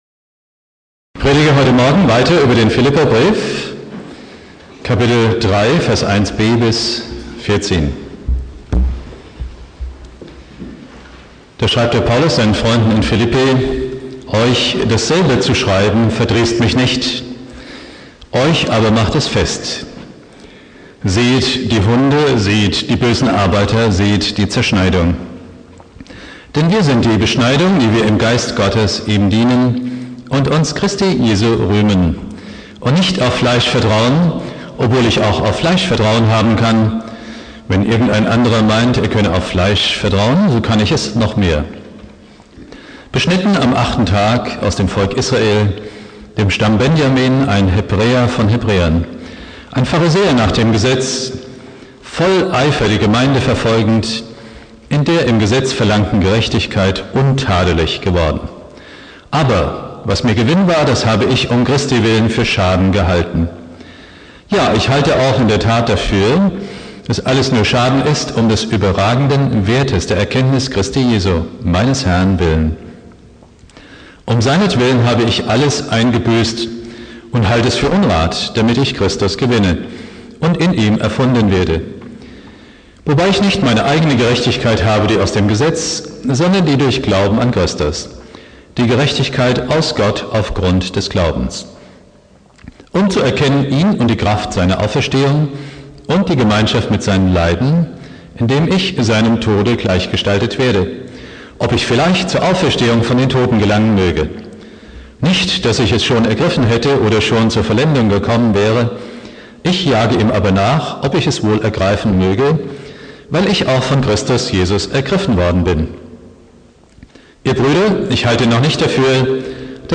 Predigt
(schlechte Aufnahmequalität) Bibeltext: Philipper 3,1-14 Dauer